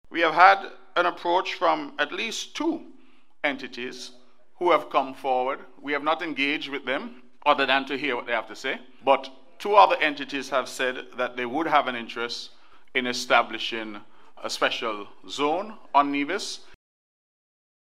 Premier, the Hon. Mark Brantley provided this response:
Premier, Mark Brantley.